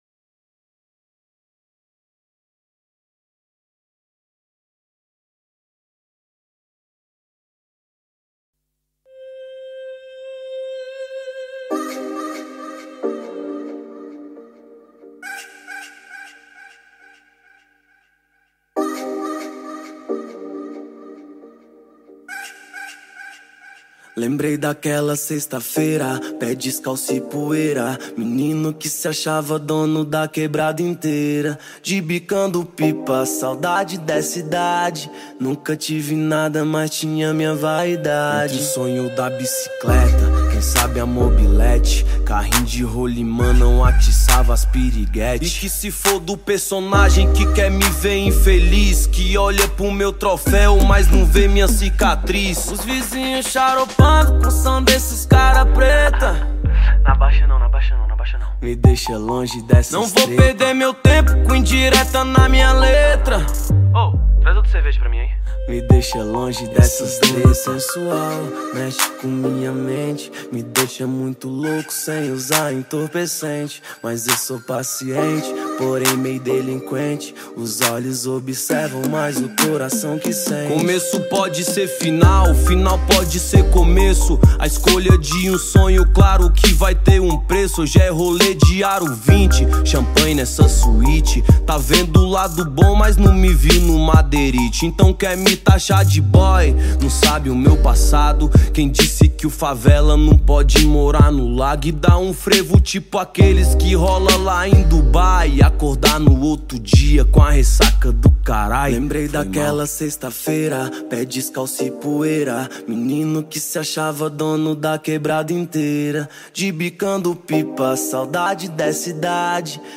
2024-05-10 21:13:32 Gênero: Hip Hop Views